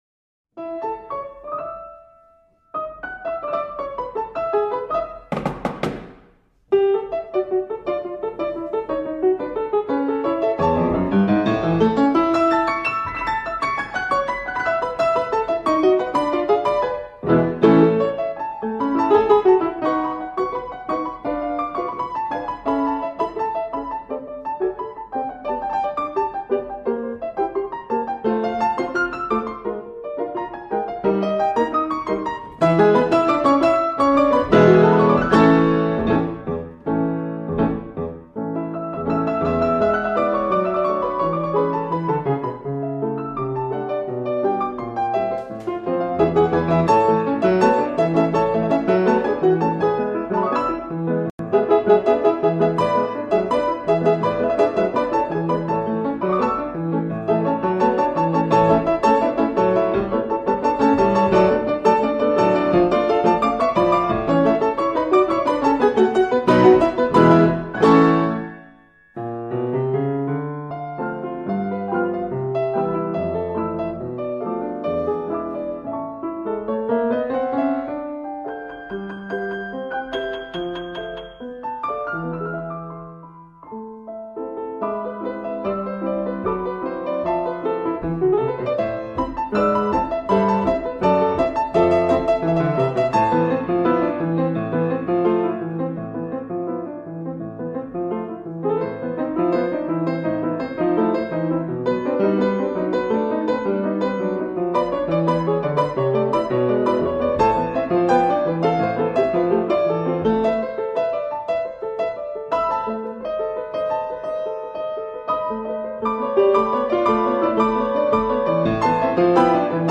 从风月场开始的勾人的舞步 乐谱咨询点击上方：联系我们